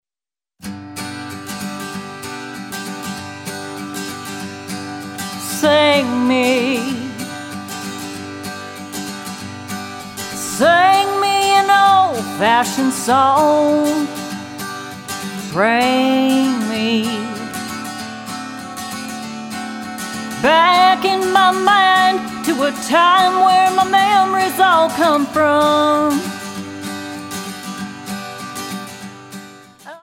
Tonart:G-Ab-A Multifile (kein Sofortdownload.